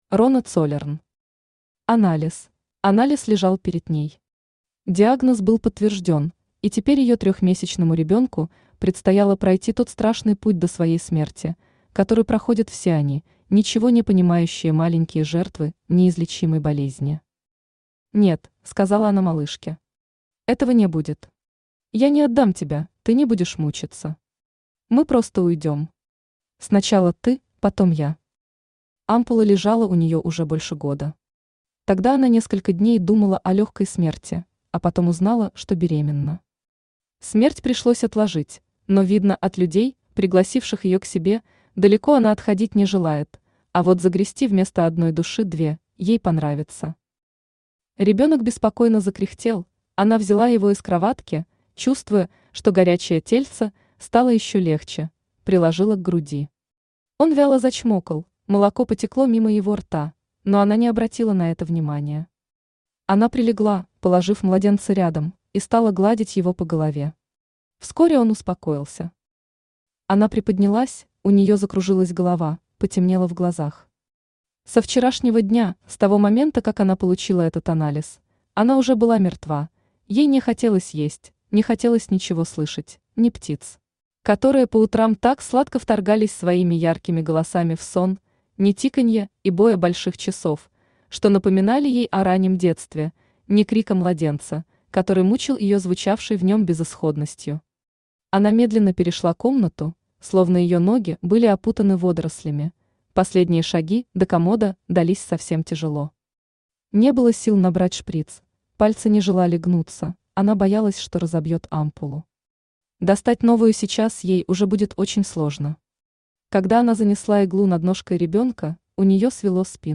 Аудиокнига Анализ | Библиотека аудиокниг
Aудиокнига Анализ Автор Рона Цоллерн Читает аудиокнигу Авточтец ЛитРес.